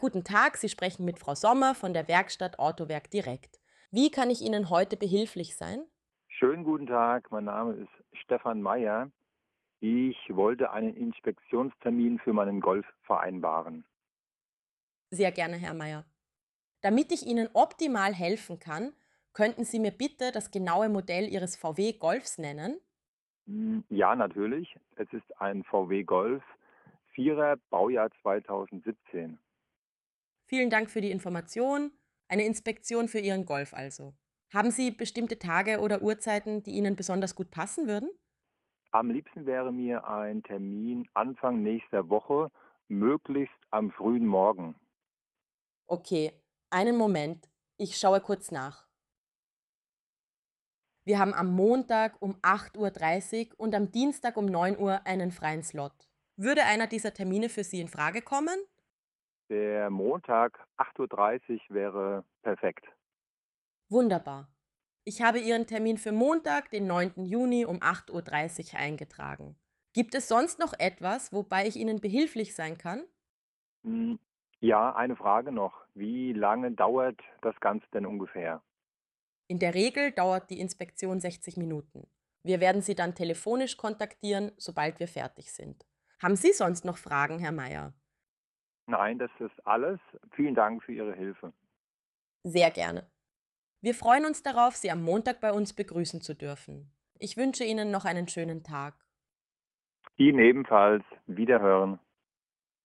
Beispiele von KI Tele-Mitarbeitern
Werkstatt_KI_Telefonat_Beispiel.mp3